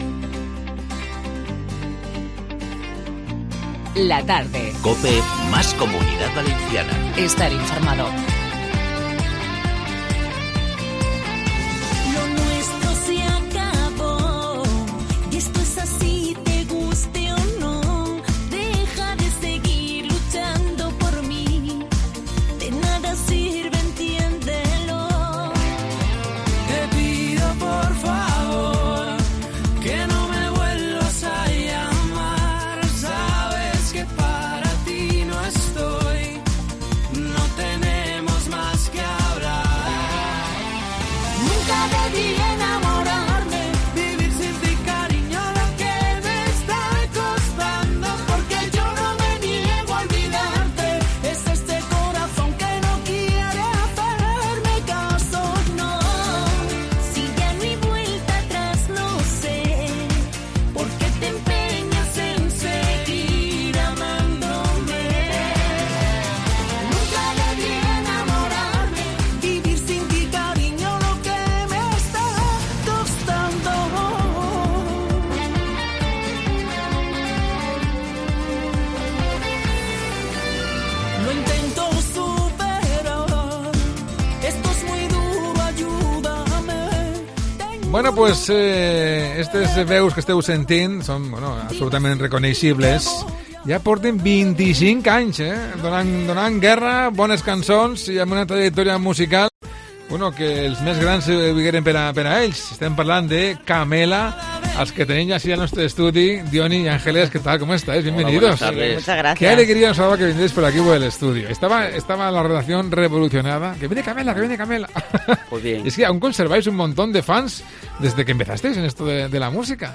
La Tarde de COPE Valencia | entrevista a Camela 21.03.2019